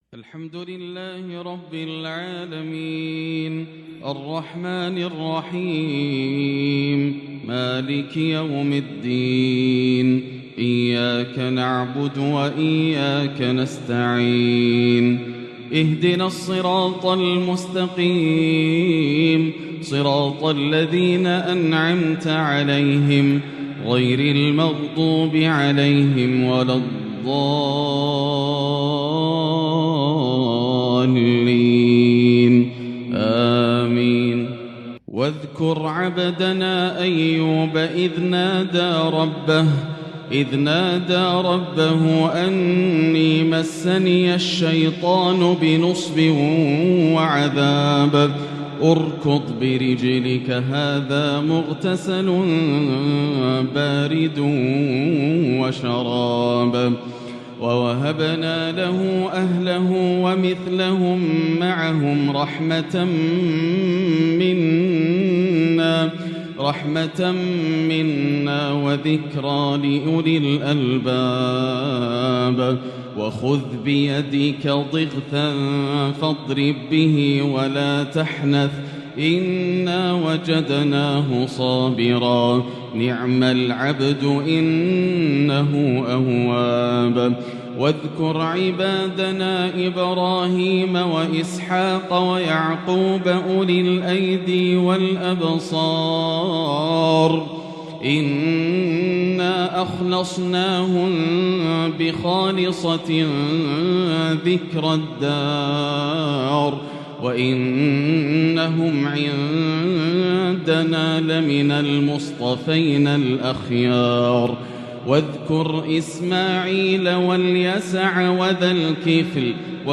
قارئ مذهل حبر كتاب ربه تحبيراً .. د.ياسر الدوسري في تلاوات عجيبة من فجريات شوال 1443هـ > تلاوات عام 1443هـ > مزامير الفرقان > المزيد - تلاوات الحرمين